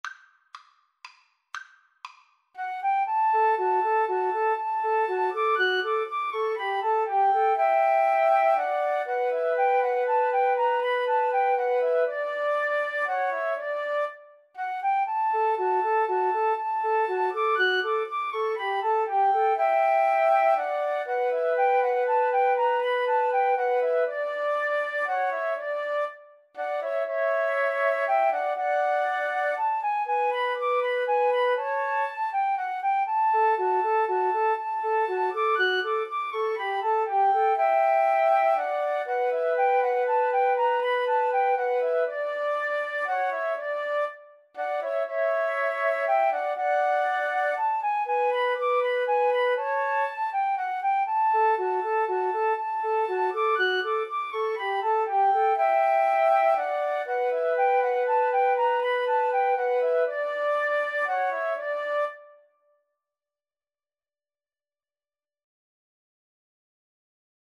3/4 (View more 3/4 Music)
Classical (View more Classical Flute Trio Music)